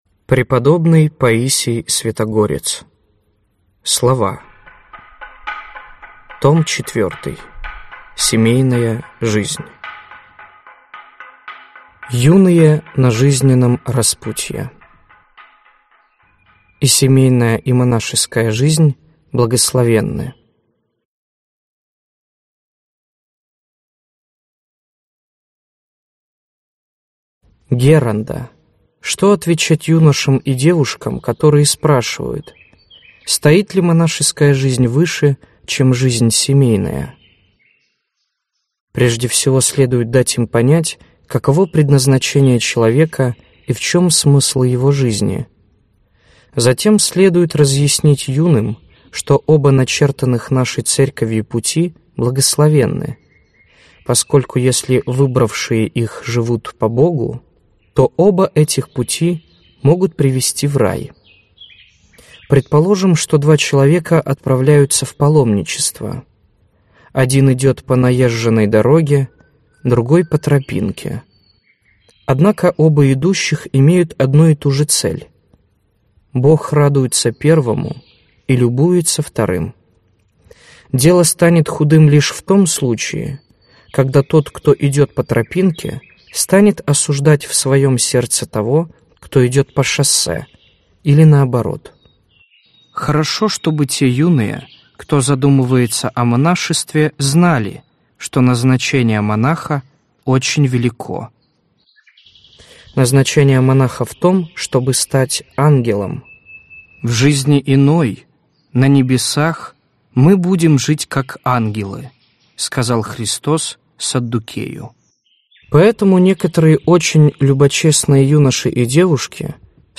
Аудиокнига Слова. Том IV. Семейная жизнь | Библиотека аудиокниг